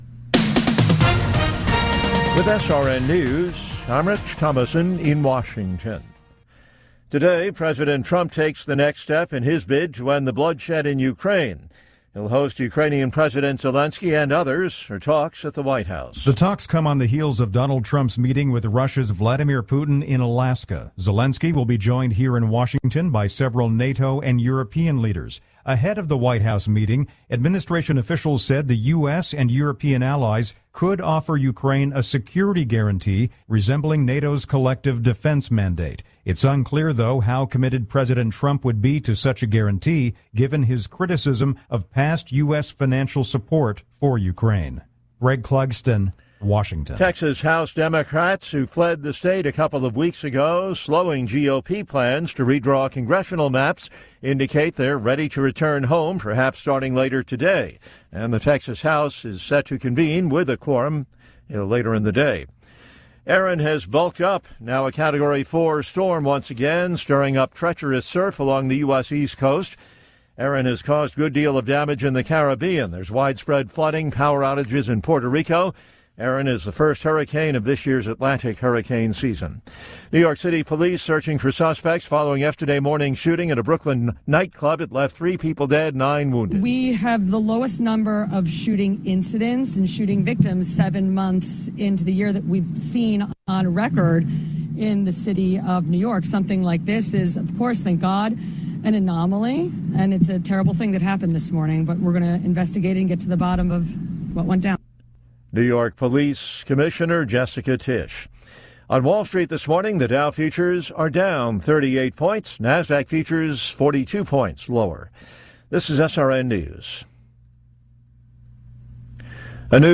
Top News Stories Aug 18, 2025 – 07:00 AM CDT